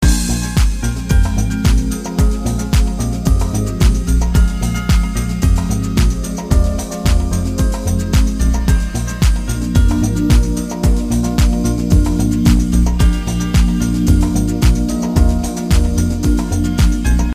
ambient house track.